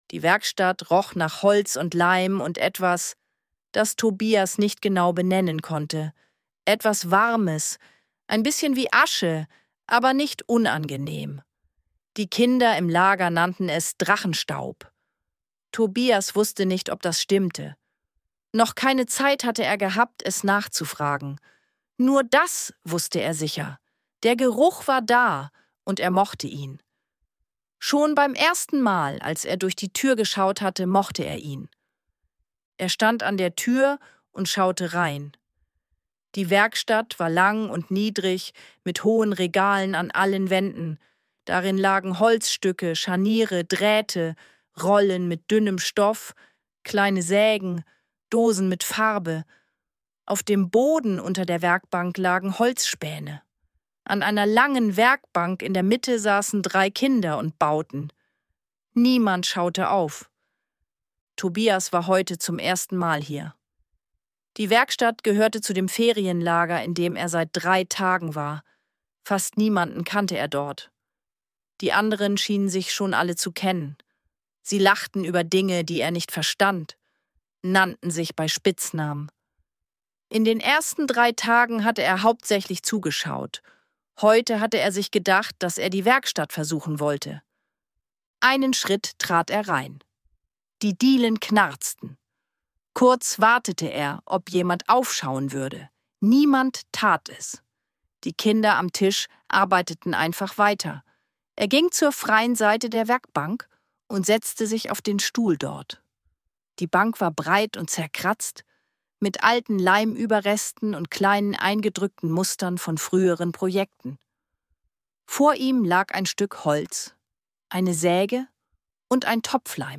Ruhige Kindergeschichten zum Anhören